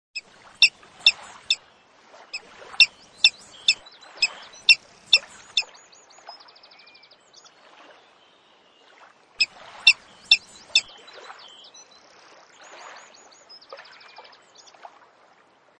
Steinadler
Der Ruf des Steinadlers ist am ehesten in der Nähe seiner Horste zu hören. Mit einer Flügelspannweite von bis zu 2,20 m und einem Gewicht von ca. 5 kg zählt der Steinadler zu den größten Greifvögeln.
steinadler.mp3